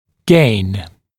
[geɪn][гейн]улучшение; положительный результат; получать (чаще с положительным оттенком смысла)